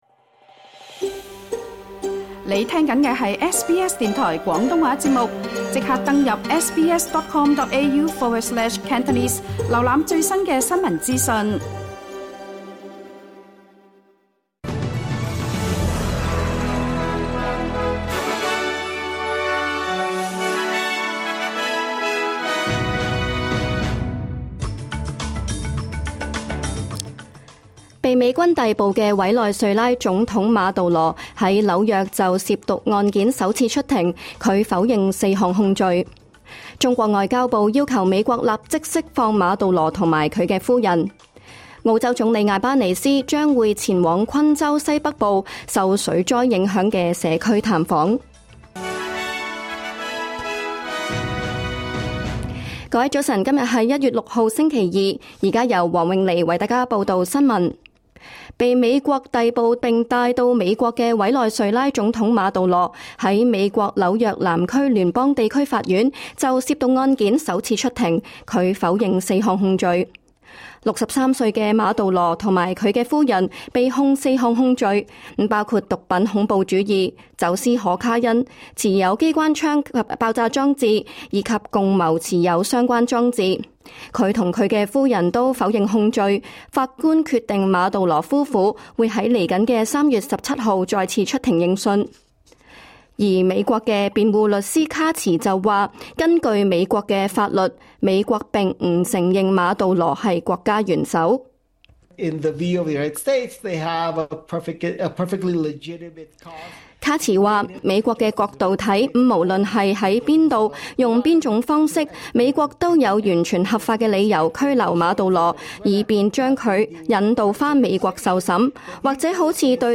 2026 年 1月 6 日 SBS 廣東話節目詳盡早晨新聞報道。